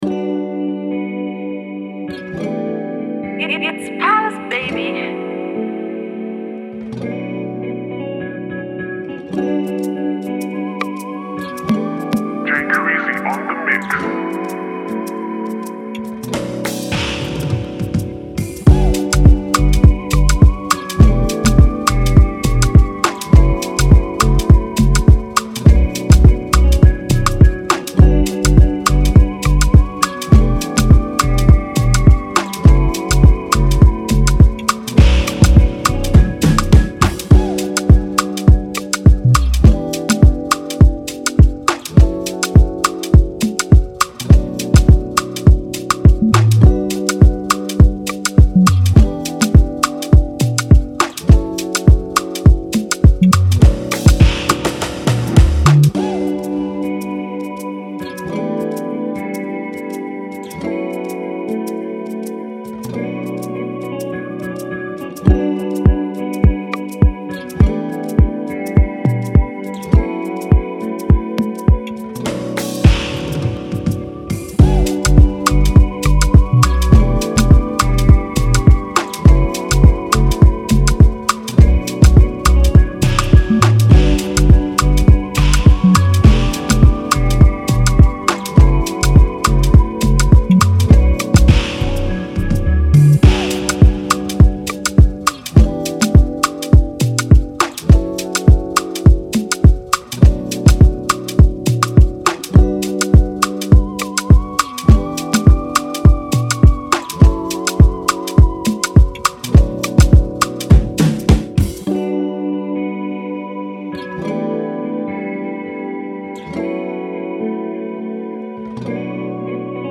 infectious rhythms